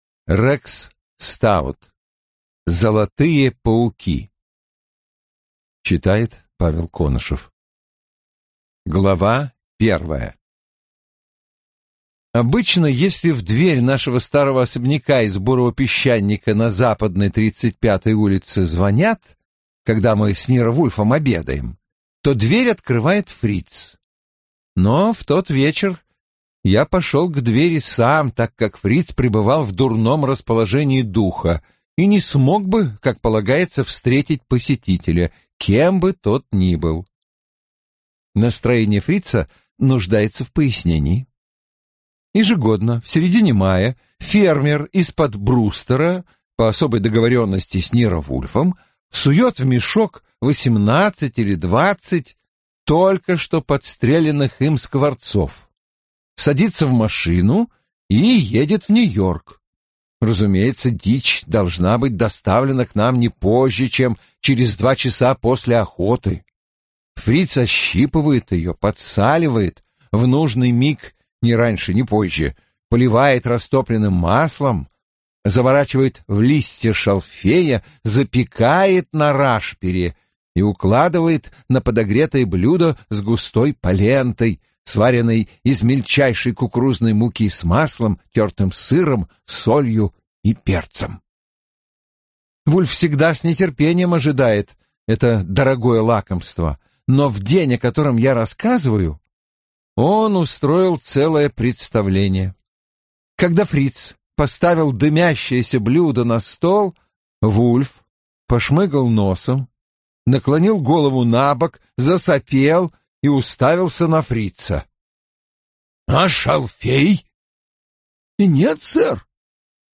Аудиокнига Золотые пауки | Библиотека аудиокниг